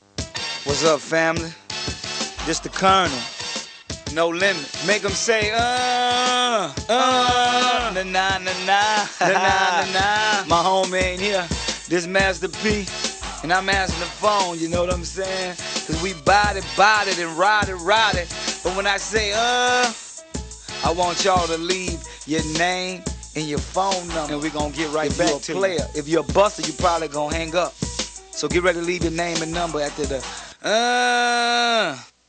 Master P's Answering Machine Message